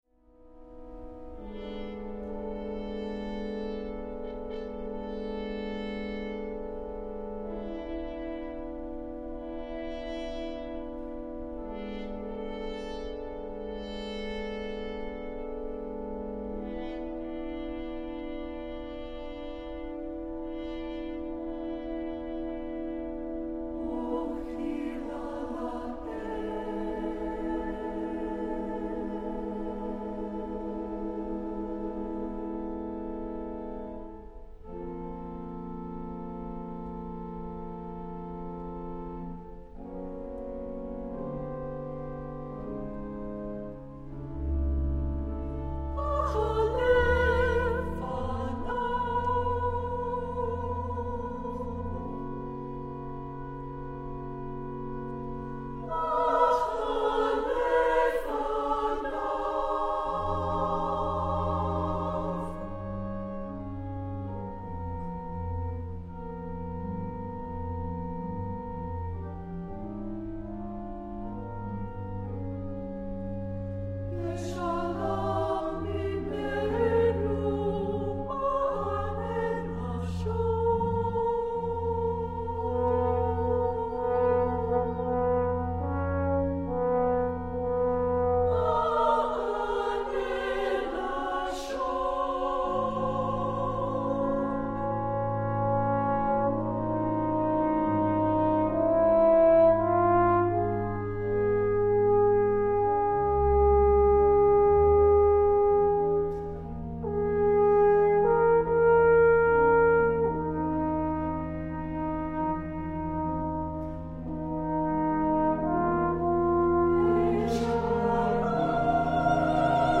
for mixed chorus, horn and organ